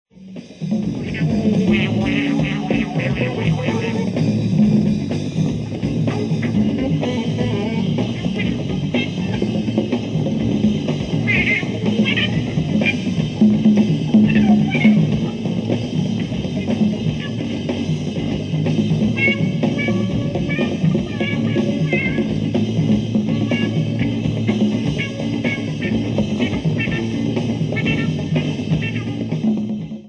SOUNDBOARD RECORDING